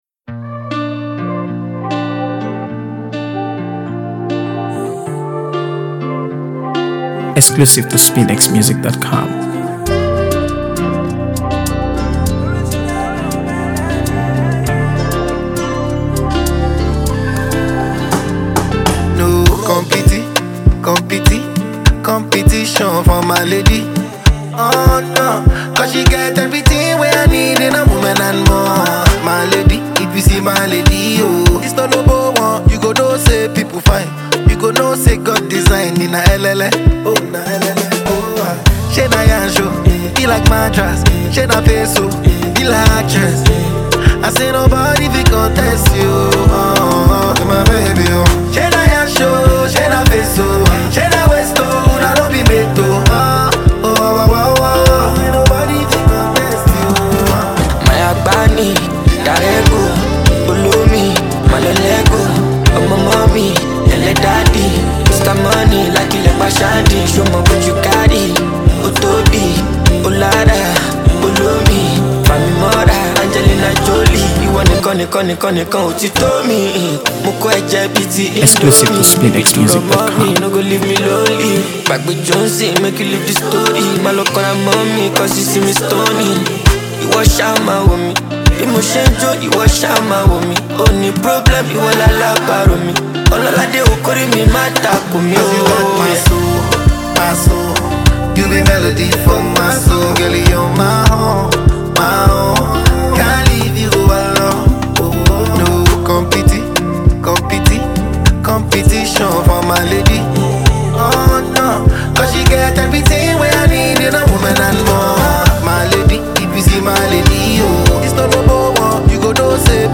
AfroBeats | AfroBeats songs
blending infectious rhythms with catchy lyrics.
With its upbeat tempo and engaging melody